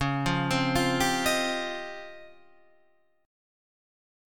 DbM#11 chord